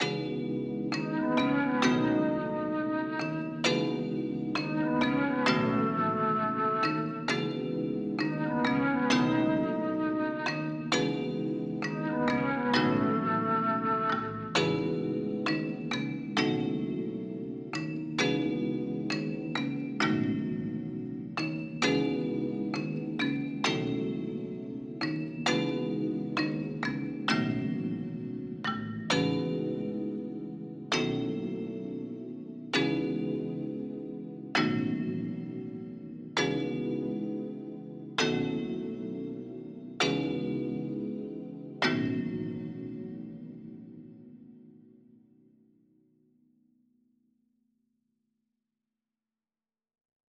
132BPM, C# Minor